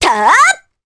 Reina-Vox_Attack2_jp.wav